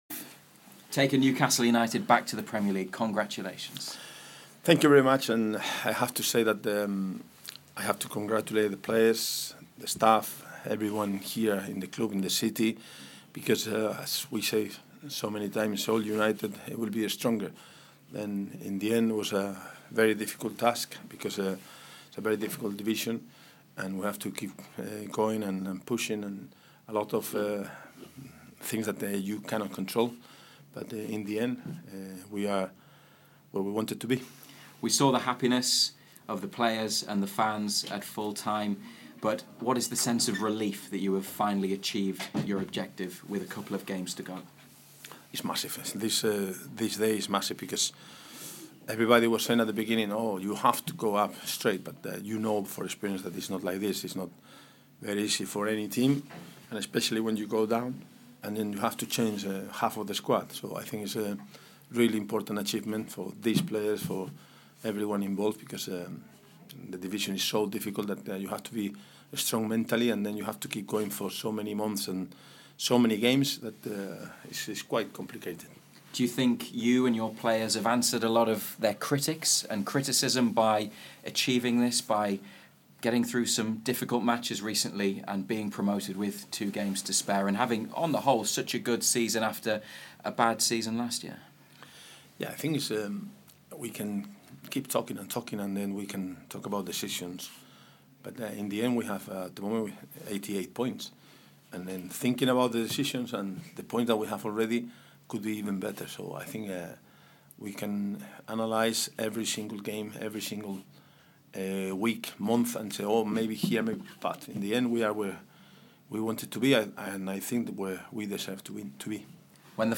Rafa Benítez spoke to BBC Newcastle after the Magpies secured promotion to the Premier League.